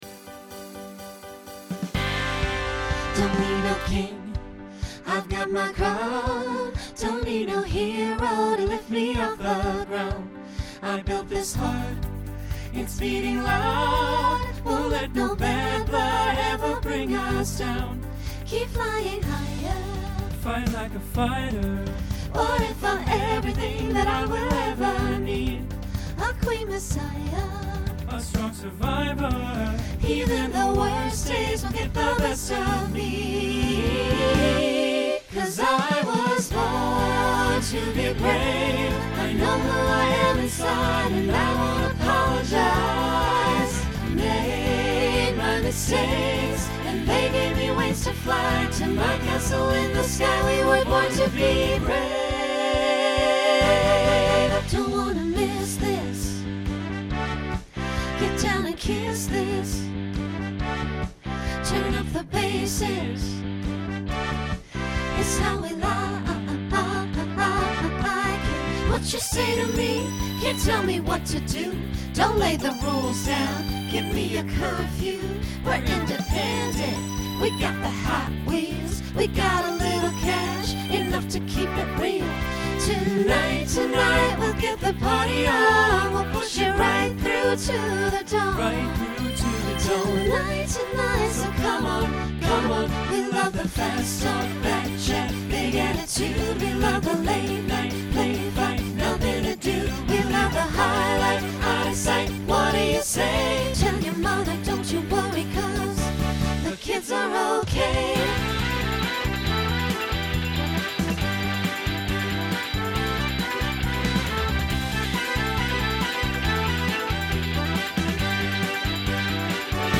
Pop/Dance
Voicing SATB